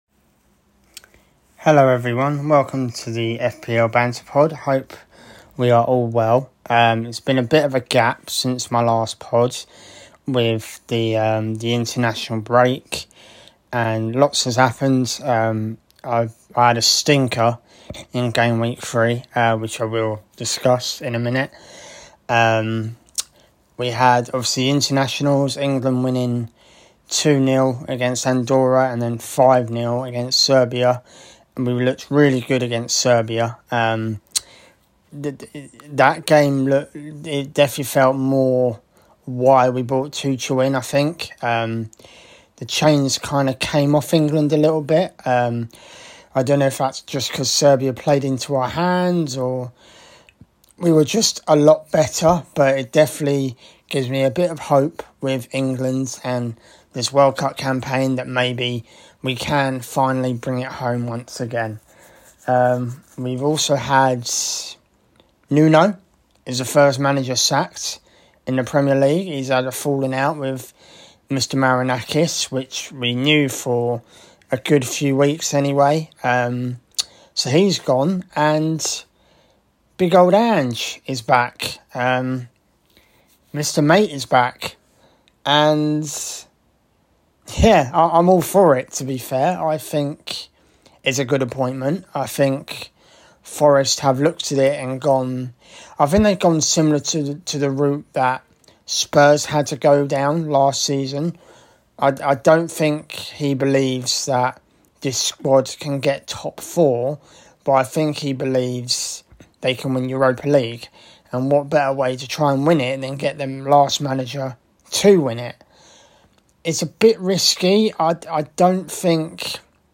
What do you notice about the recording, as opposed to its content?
on a zoom call to help me sort this team out !